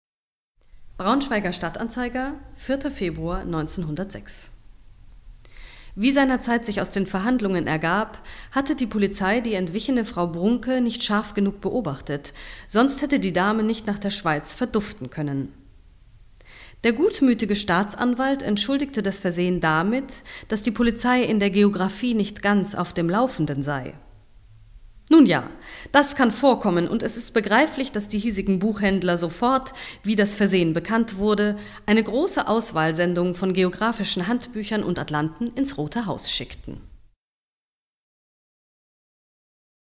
Stimme
sprecherin5.mp3